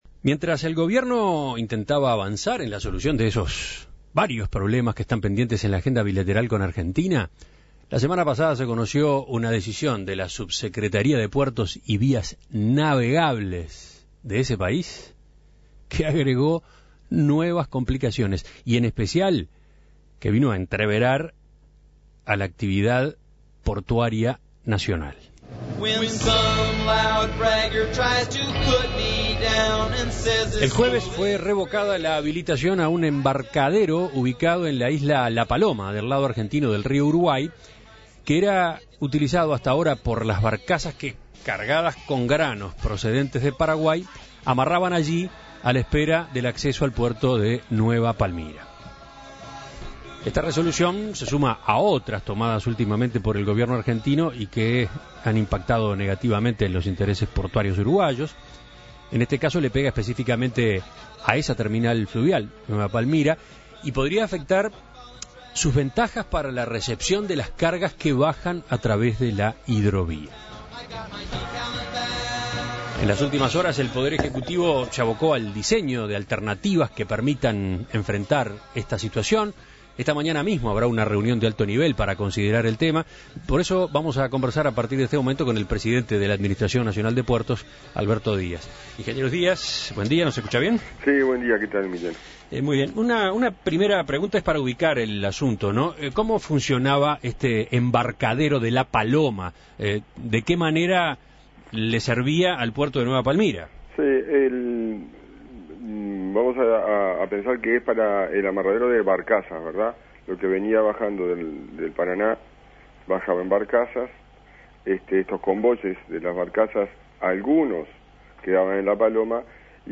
En Perspectiva consultó al respecto a Alberto Díaz, presidente de la Administración Nacional de Puertos (ANP), quien explicó el funcionamiento de dicho embarcadero y las posibles repercusiones de esta nueva medida.